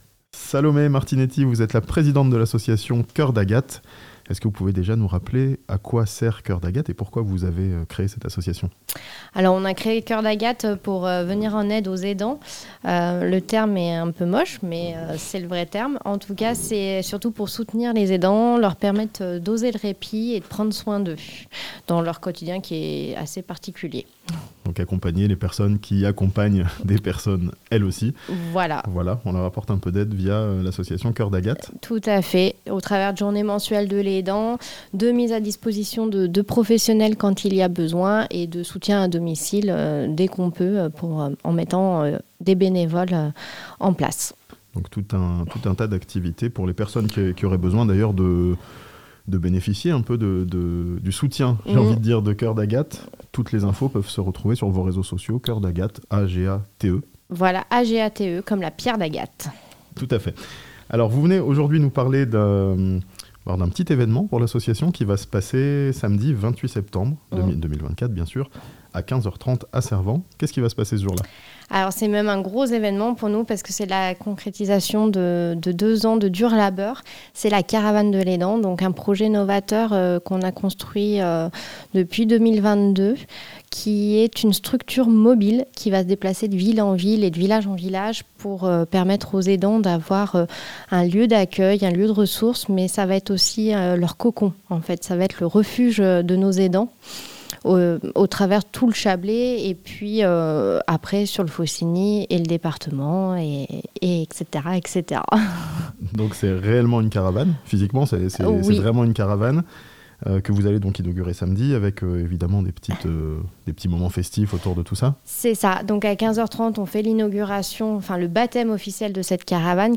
L'association Coeur d'Agate inaugurera sa "caravane des aidants" samedi 28 septembre à Cervens (interview)